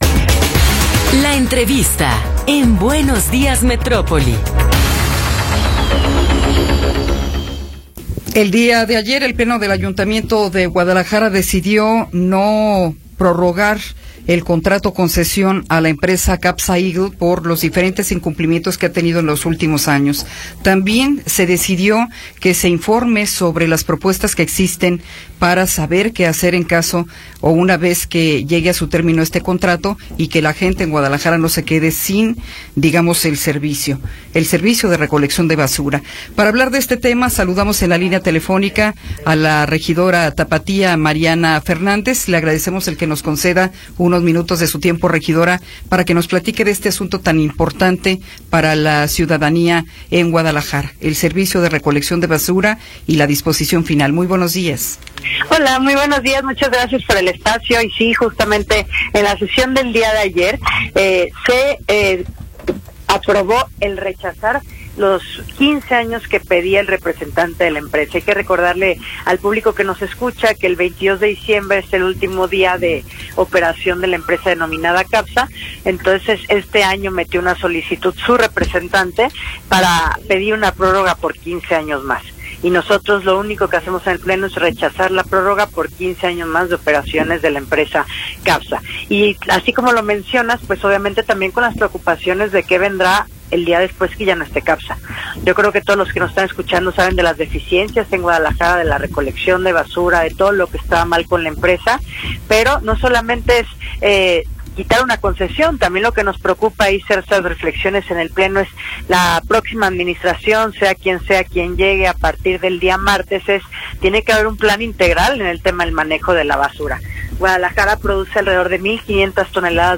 Entrevista con Mariana Fernández Ramírez